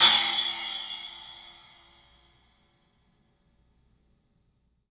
Index of /90_sSampleCDs/E-MU Producer Series Vol. 5 – 3-D Audio Collection/3DPercussives/3DPACymbals